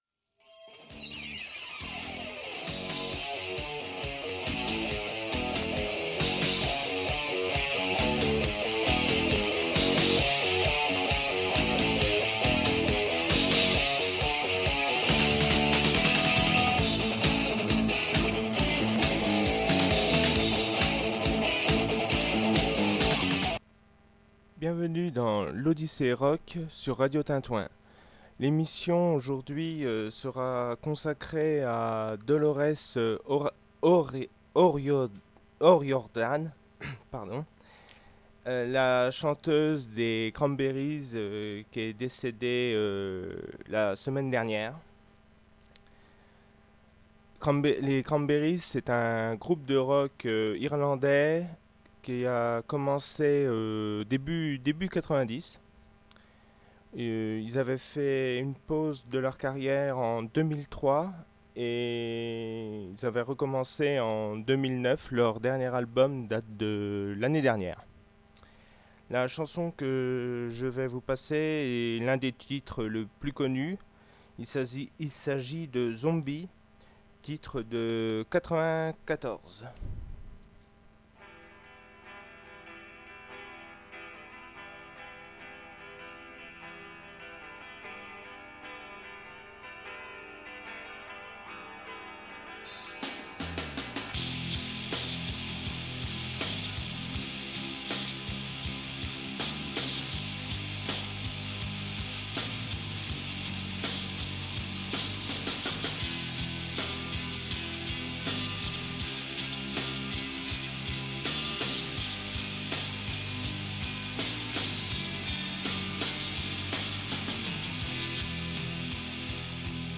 Beaucoup d’émotion s’est rajouté à mon stress habituel ce qui rend mon dialogue très hésitant, je m’en excuse et j’espère que vous apprécierez tout de même l’émission.